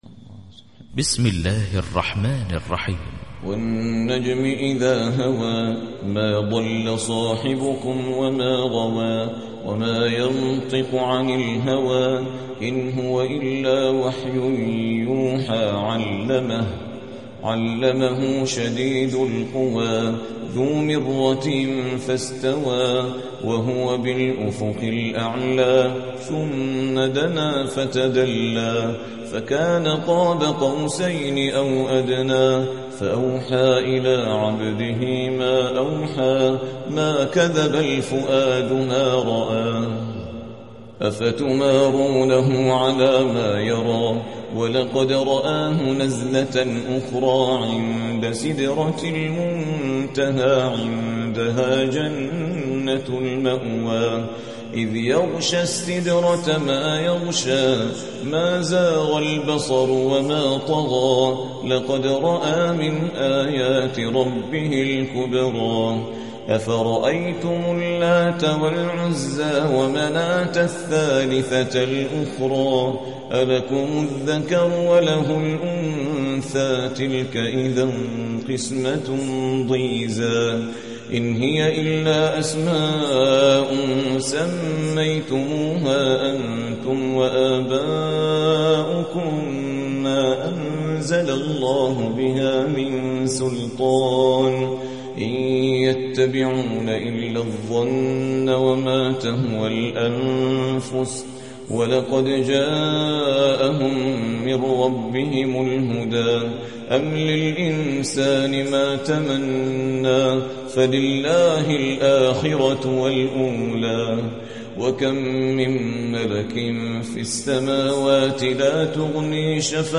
53. سورة النجم / القارئ